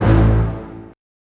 Hit (2).wav